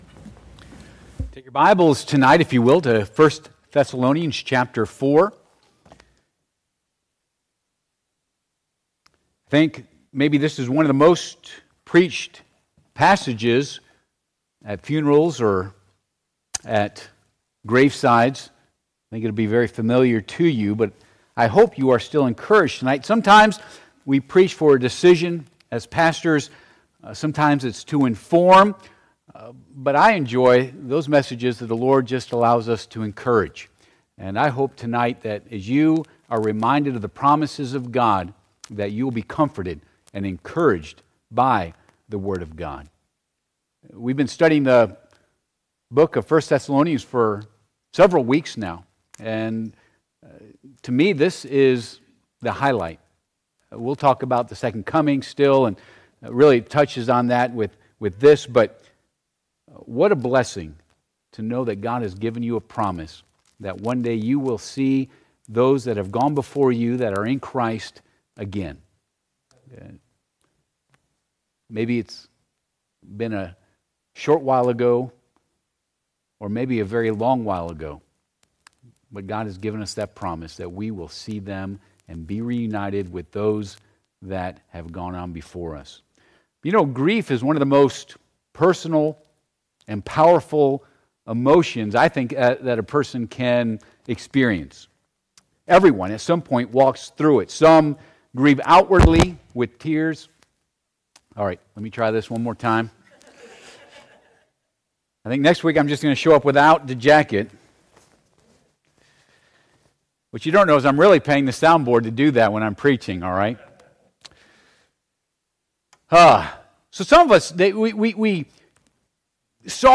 I Thessalonians Passage: I Thess. 4:13-18 Service Type: Midweek Service « Persistance Pays Tola